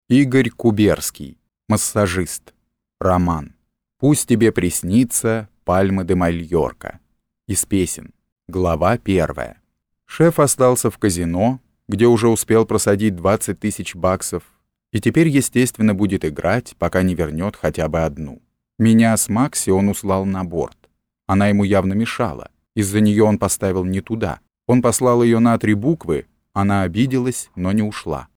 Аудиокнига Массажист | Библиотека аудиокниг